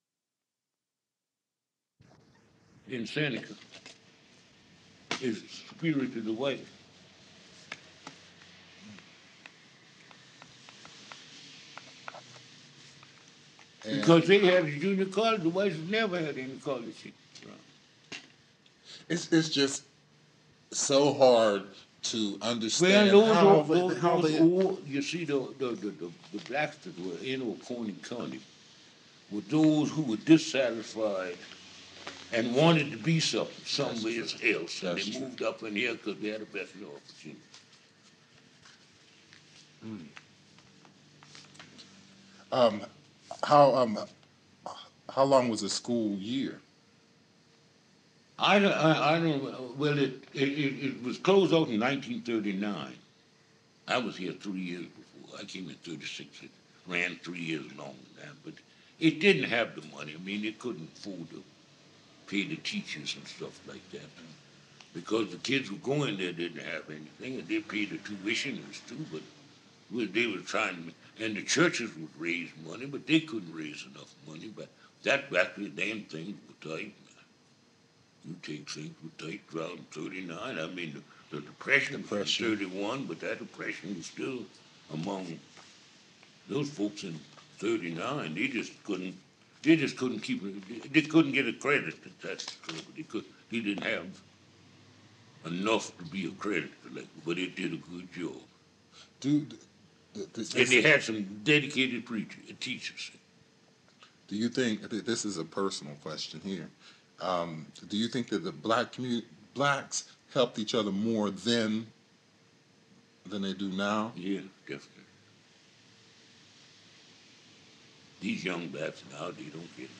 Cassette 2